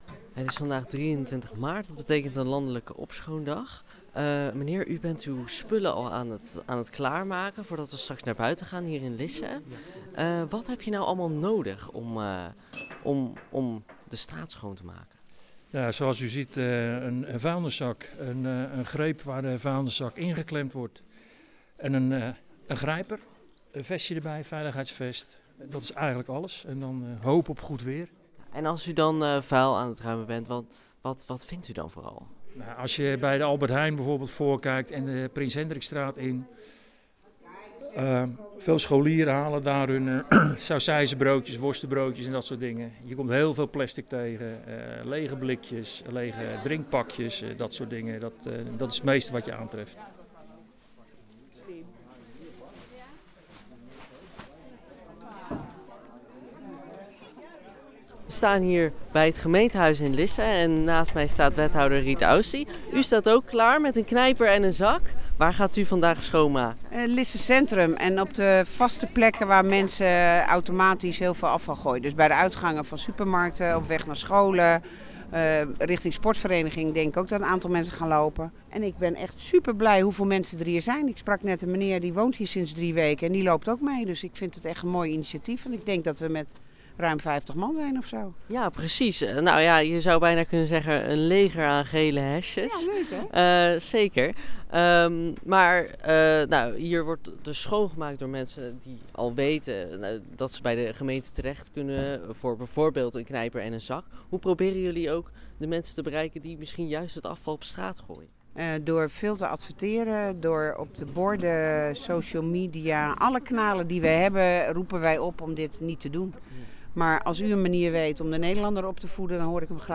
liep mee met een aantal opruimers in Lisse: